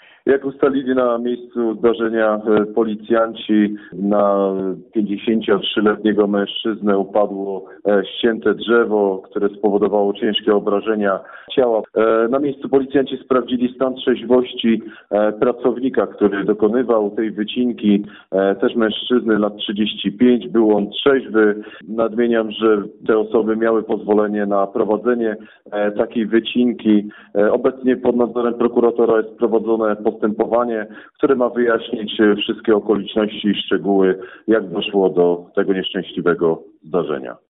Do zdarzenia doszło około godziny 14.40, mówi nadkom.